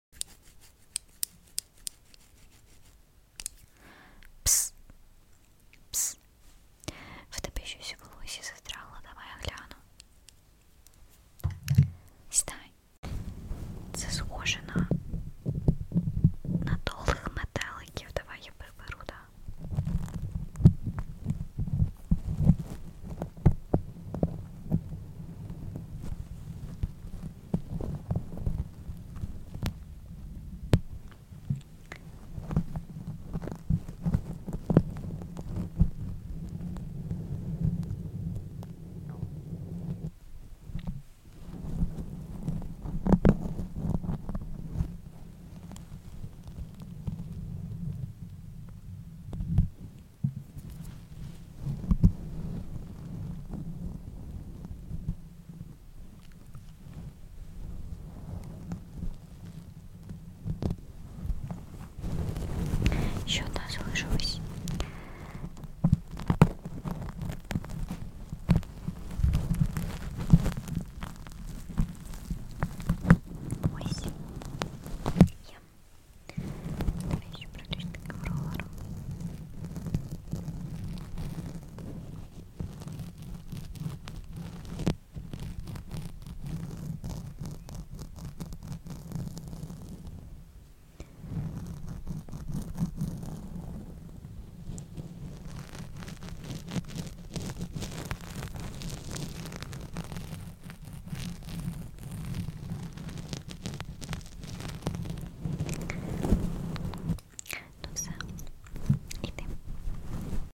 ASMR